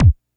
kick01.wav